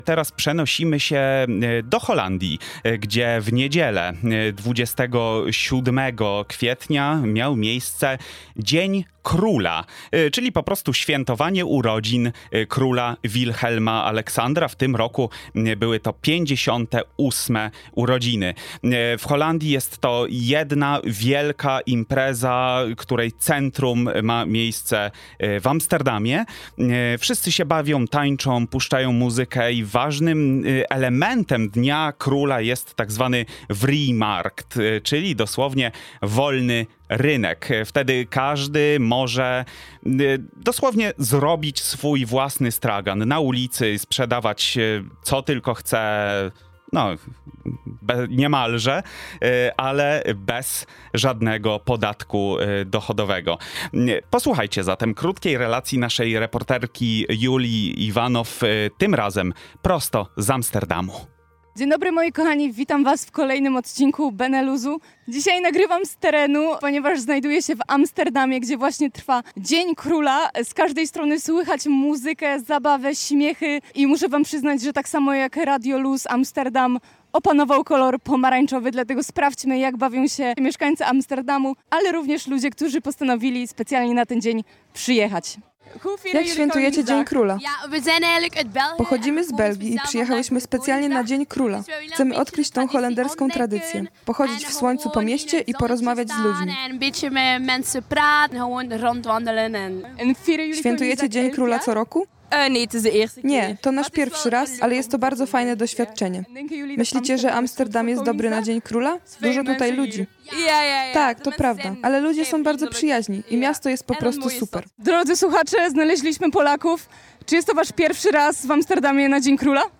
Na miejscu mieliśmy jednak naszych dziennikarzy, którzy opowiedzieli, jak wygląda ten wyjątkowy dzień w kraju tulipanów.
Więcej dowiecie się w naszym fragmencie audycji Dzieje się: